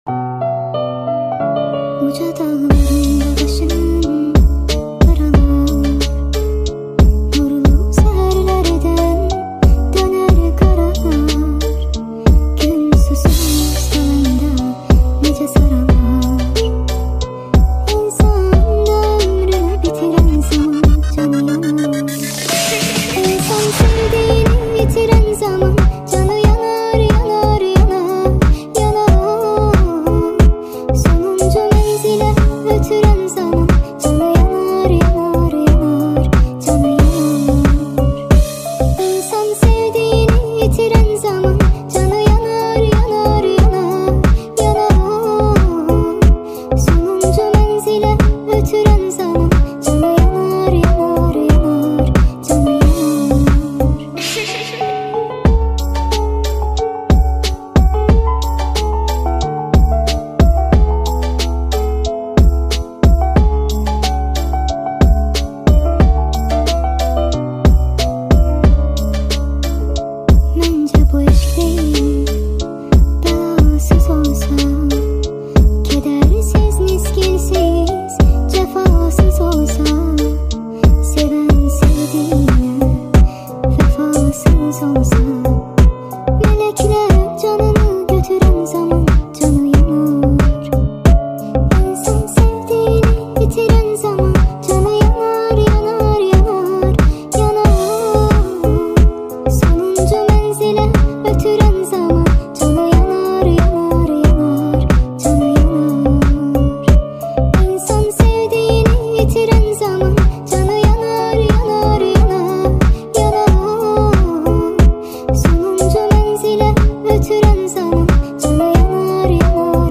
ریمیکس افزایش سرعت